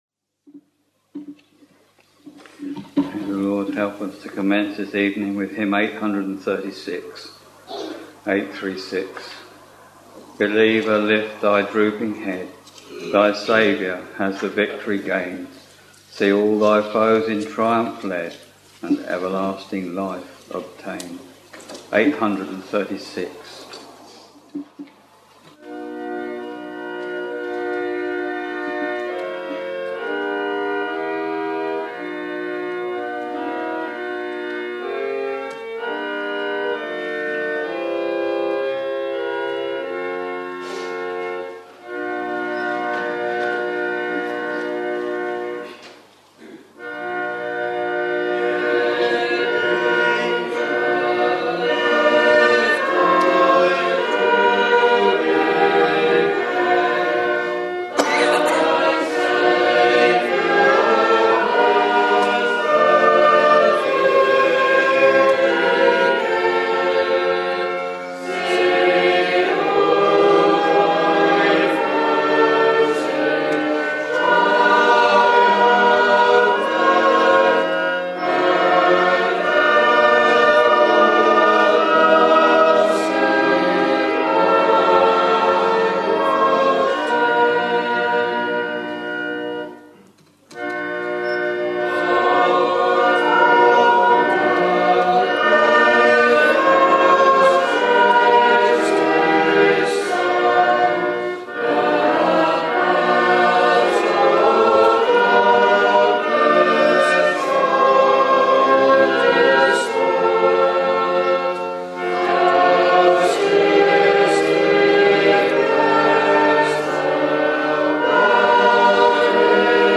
We are very pleased for you to listen to the live or archived services if you are not able to assemble for public worship in your local church or chapel.